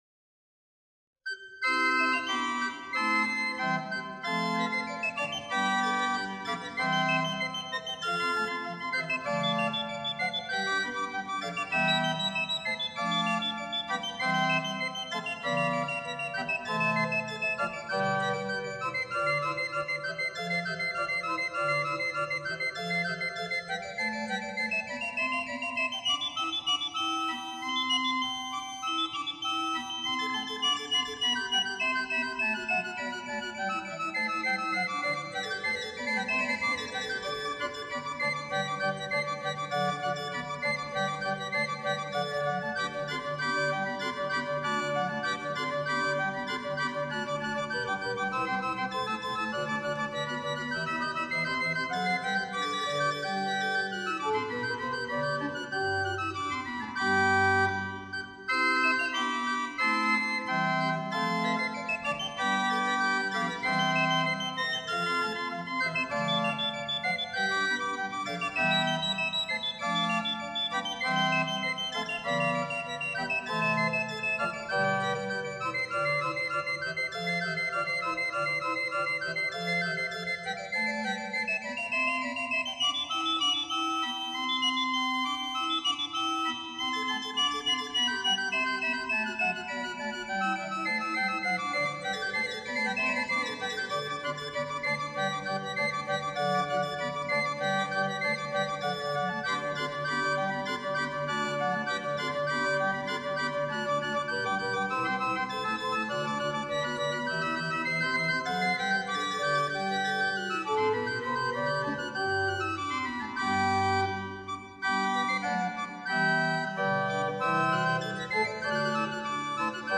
Stopped Diapason, 15th
Stopped Diapason, Principal, 15th